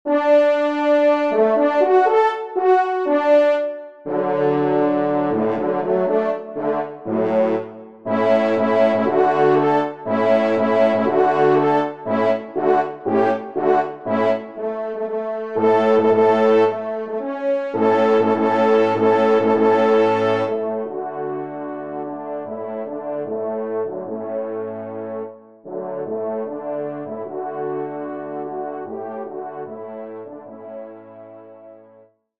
Genre : Divertissement pour Trompes ou Cors
Trompe 5 (C. Basse)  (en exergue)